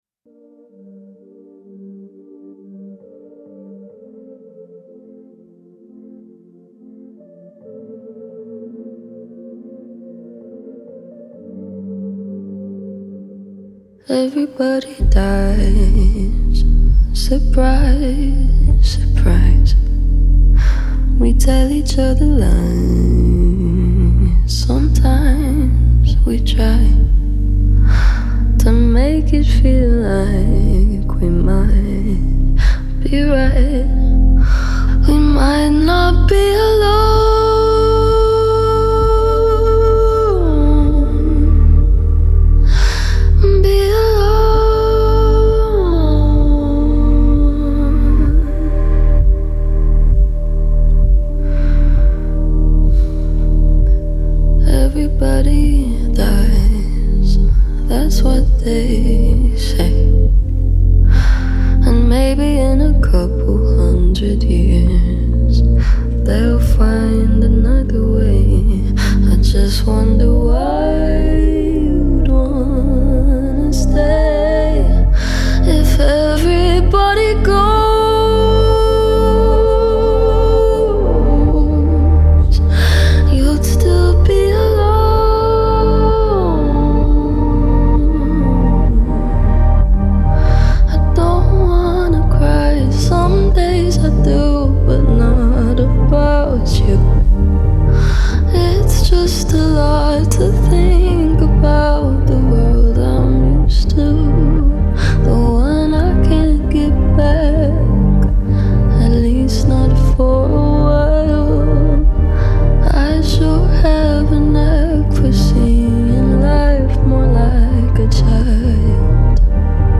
альтернативного попа